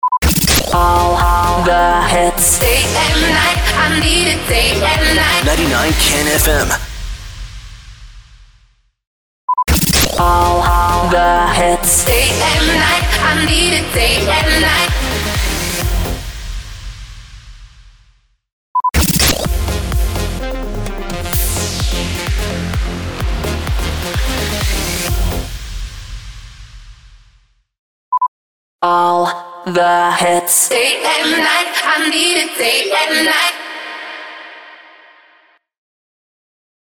416 – SWEEPER – DAY & NIGHT